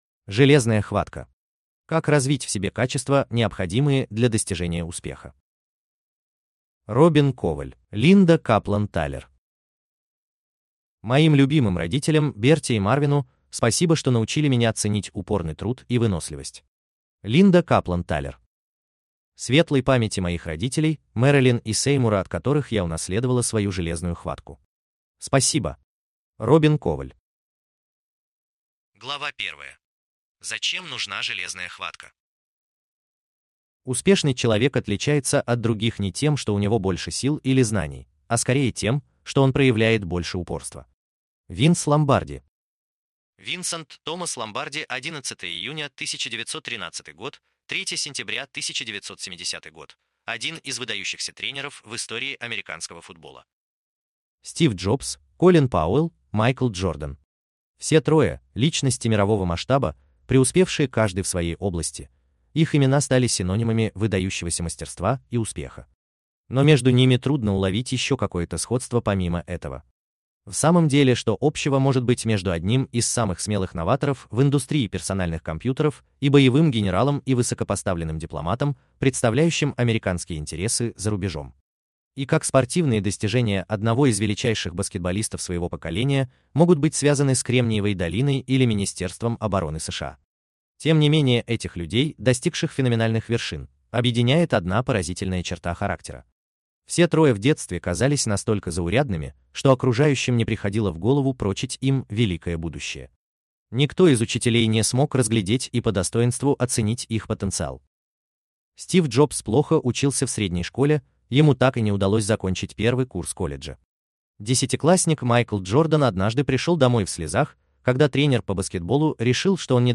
Аудиокнига Железная хватка: Как развить в себе качества, необходимые для достижения успеха | Библиотека аудиокниг